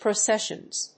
/proˈsɛʃʌnz(米国英語), prəʊˈseʃʌnz(英国英語)/